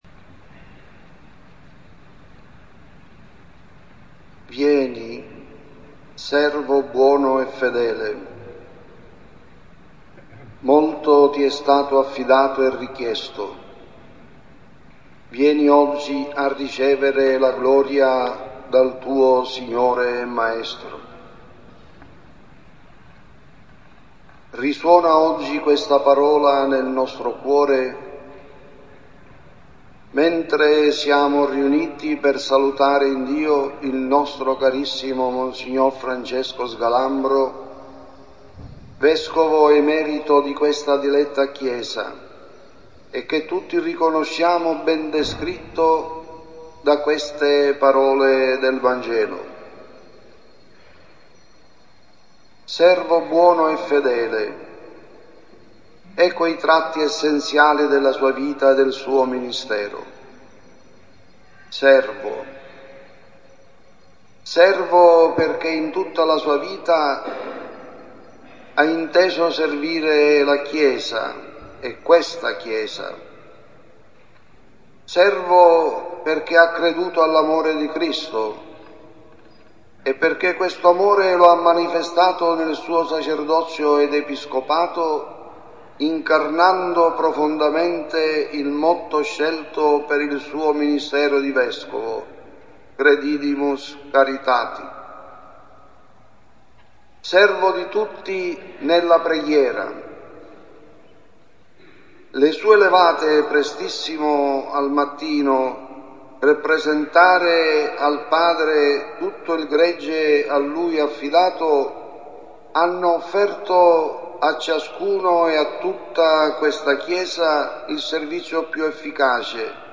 I funerali del Vescovo Sgalambro: "Servo buono e fedele" Si sono svolti questo pomeriggio i funerali di Mons. Francesco Sgalambro, Vescovo Emerito della Diocesi di Cefalù. La Celebrazione Eucaristica è stata presieduta da Mons. Vincenz Manzella, vescovo di Cefalù.
Omelia Vescovo Manzella.mp3